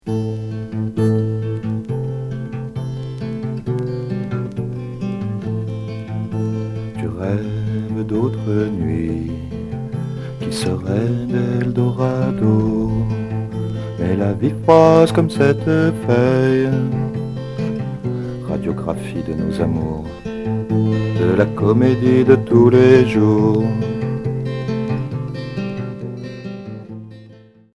Pop indé